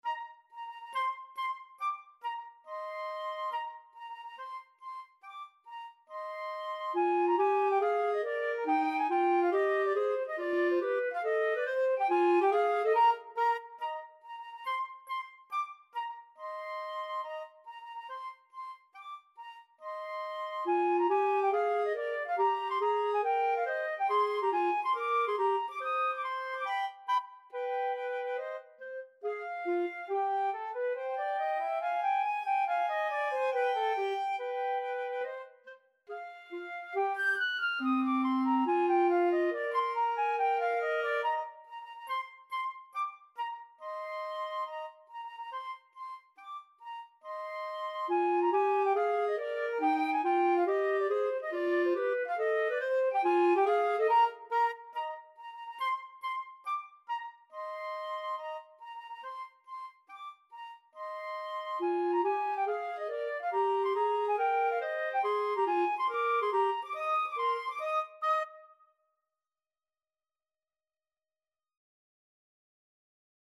4/4 (View more 4/4 Music)
Tempo di marcia =140